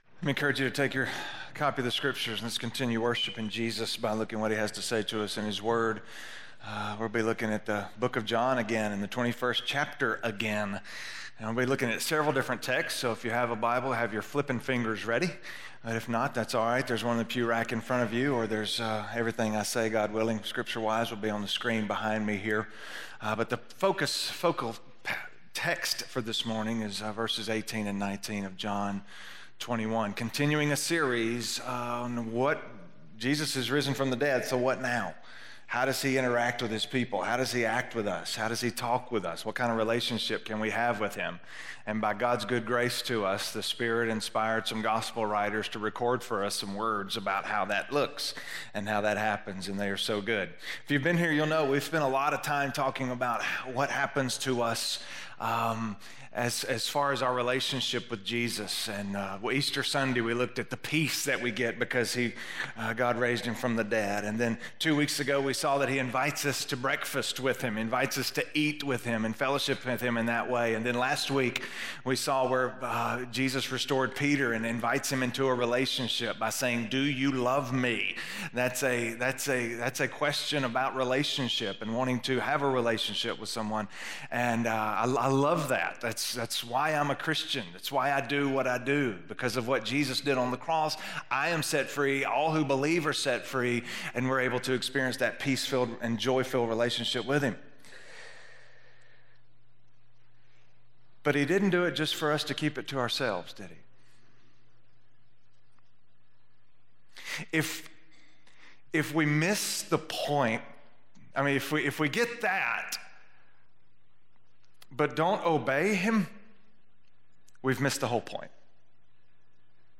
Sermons - West Franklin